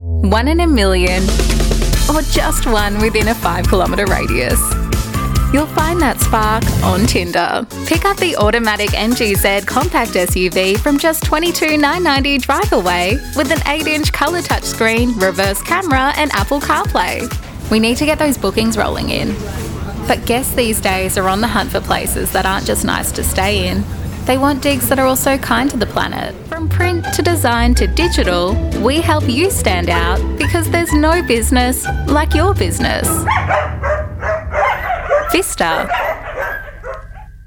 Australian female voiceover artist, with a voice often described as:
Recording from my sound engineer approved home recording studio
- Rode NT1 Microphone
Television Spots
Upbeat, Cool, Playful
1228Upbeat__cool__playful__Commercial_demo_.mp3